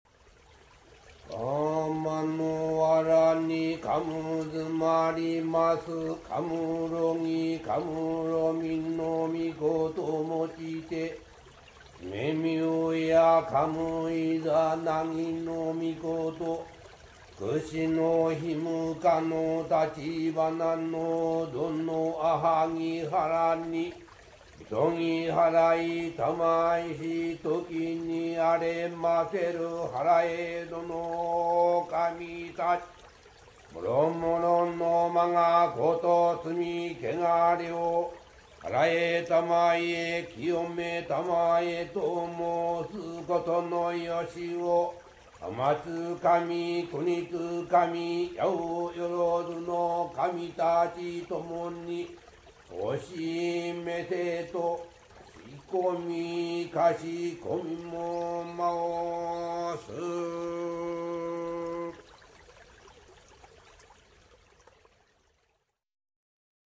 misogiwithwater.MP3